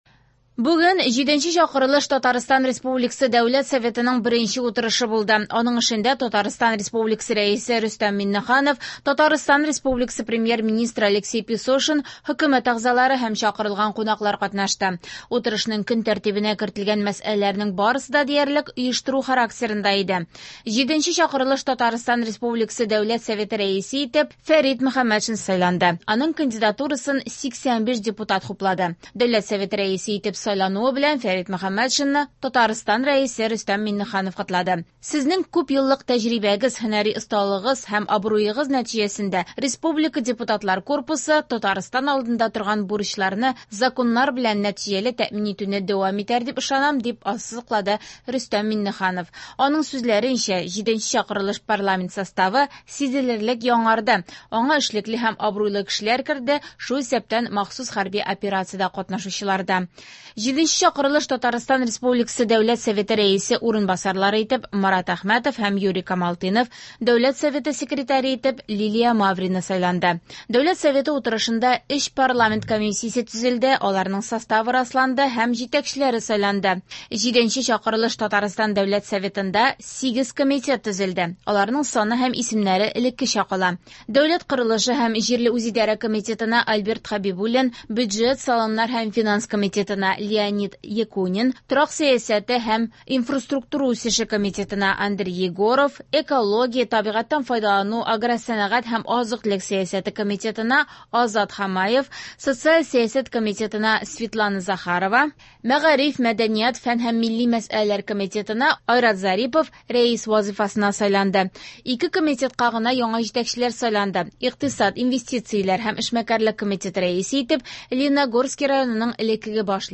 Радиохисап - Радиоотчет (20.09.24) | Вести Татарстан